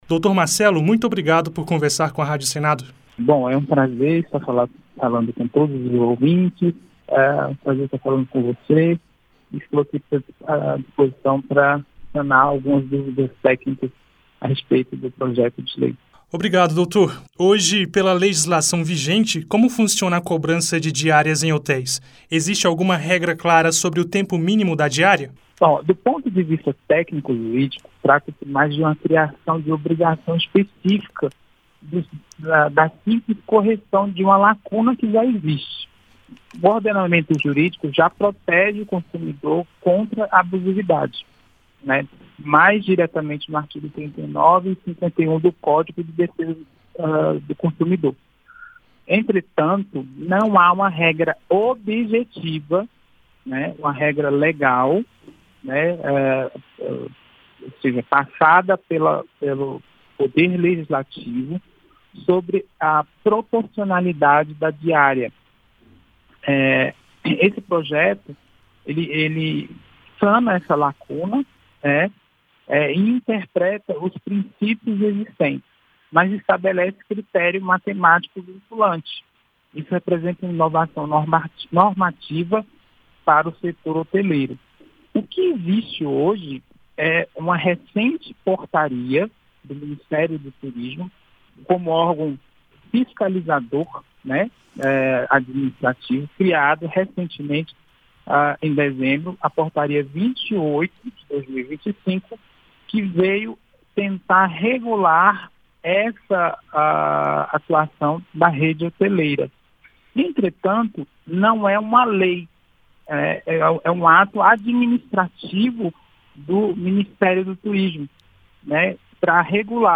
O repórter
conversou com o advogado